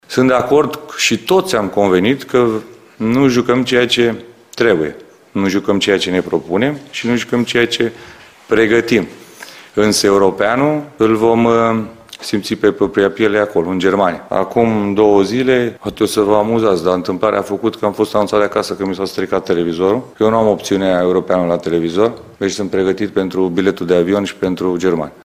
Selecţionerul Edward Iordănescu a declarat, luni, într-o conferinţă de presă, că lipsa de performanţă apasă, că lumea nu mai are răbdare şi este nemulţumită, dar consideră că echipa naţională este pe drumul cel bun.